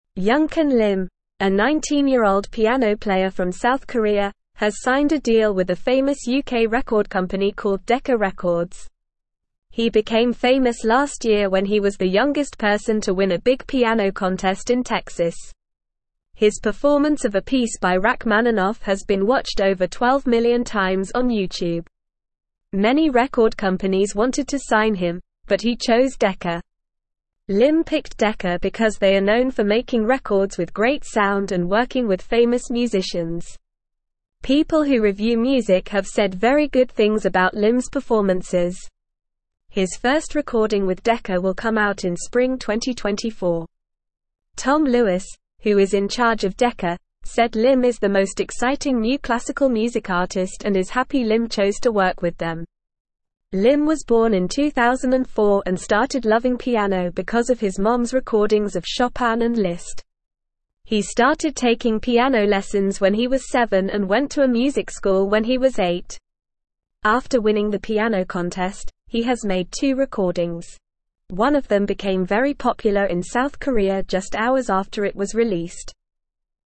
Normal
English-Newsroom-Lower-Intermediate-NORMAL-Reading-Young-Korean-Pianist-Signs-with-Famous-Music-Company.mp3